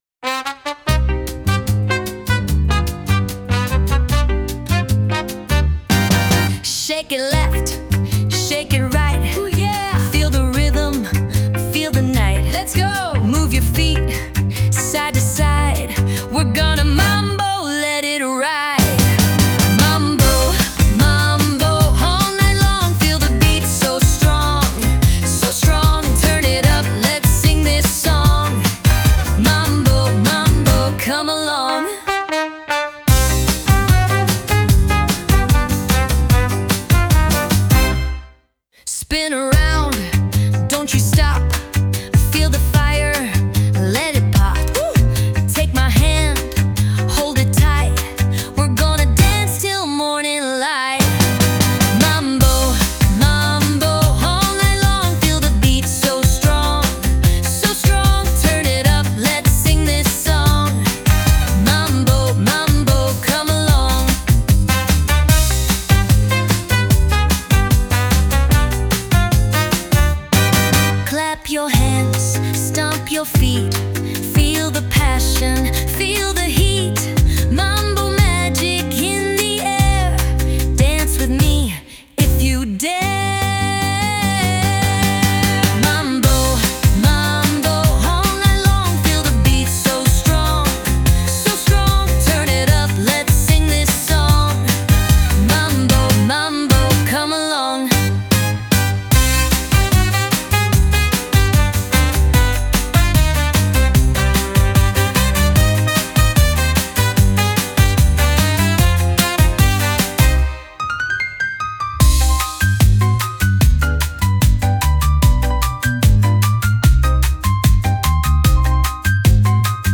With Vocals / 歌あり
スカやタンゴの要素も織り交ぜつつ、強めのサンバ感が心地よいリズムを刻む一曲。
激しすぎず、まったりとリズムに乗って身体を動かしたくなるような、陽気でリラックスしたグルーヴが魅力です。